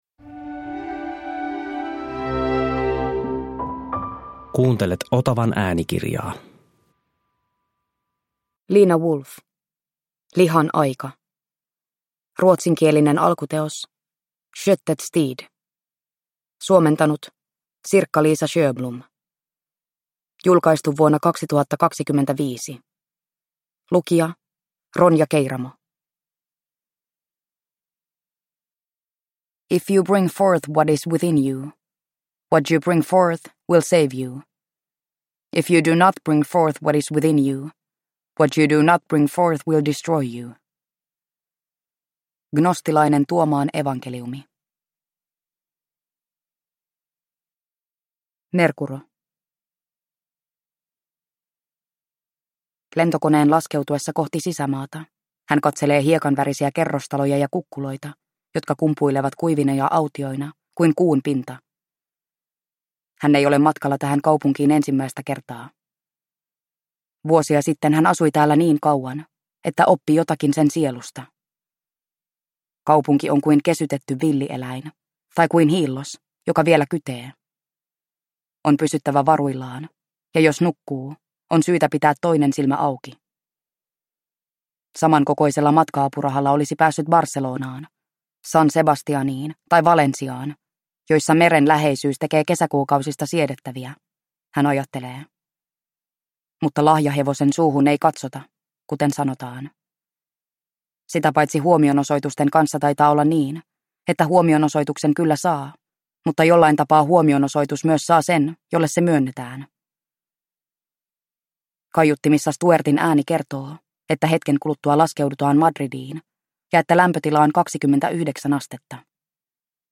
Lihan aika (ljudbok) av Lina Wolff